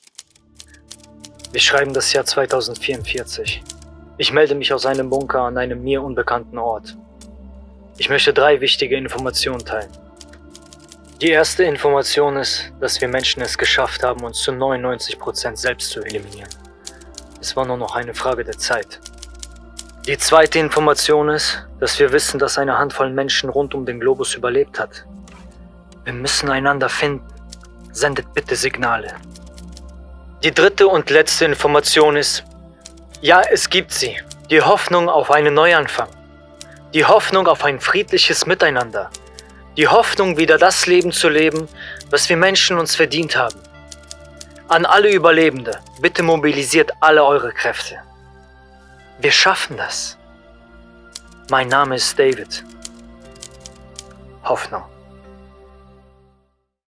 Erzählung